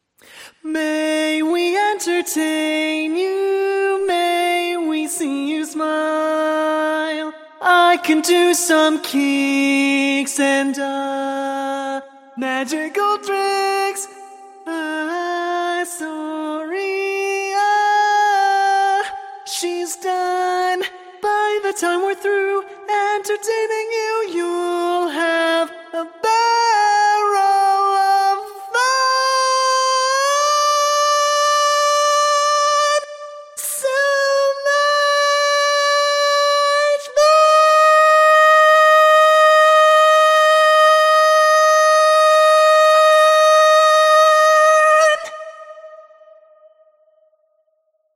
Key written in: A♭ Major
Type: Female Barbershop (incl. SAI, HI, etc)
Each recording below is single part only.